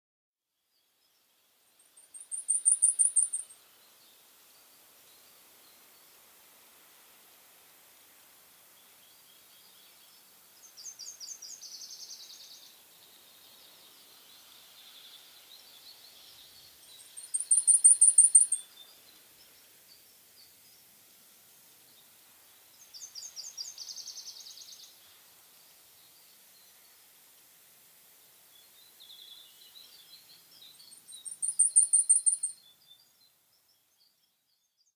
Blackpoll Warbler: Female | Hunterdon Art Museum
blacpoll-warbler.m4a